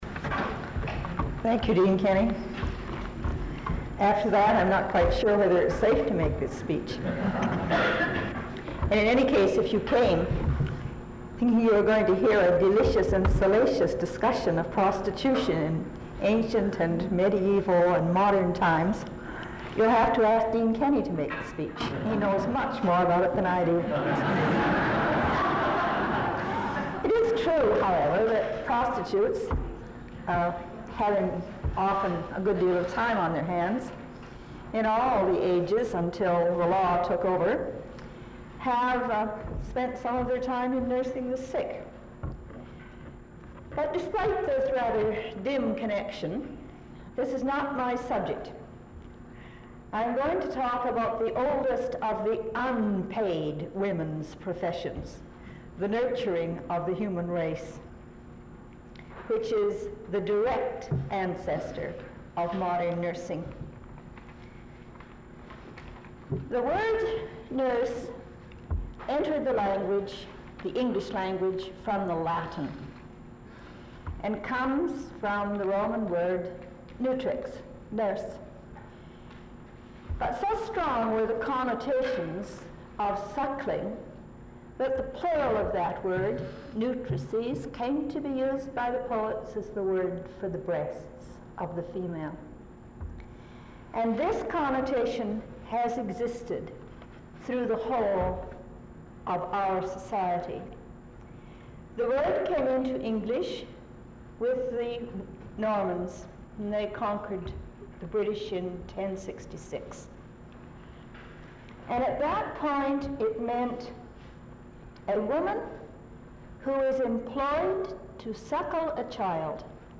Vancouver Institute lecture